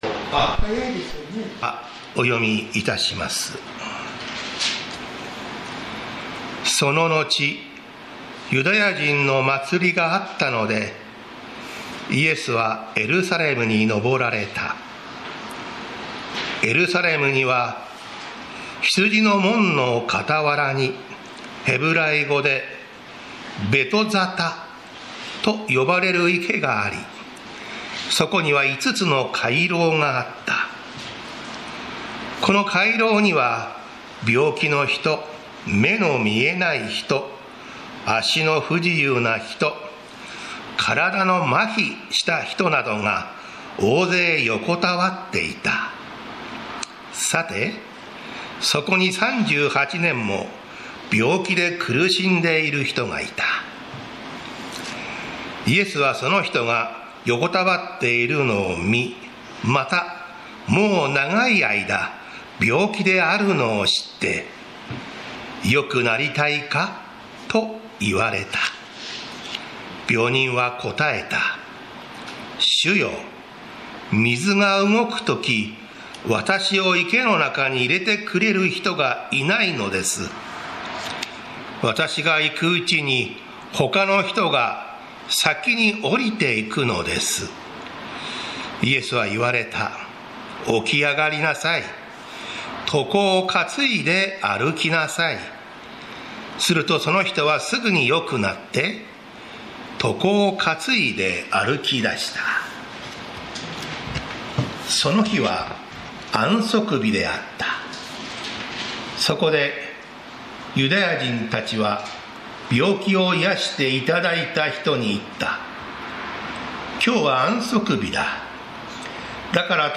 栃木県鹿沼市のプロテスタント教会。
日曜 朝の礼拝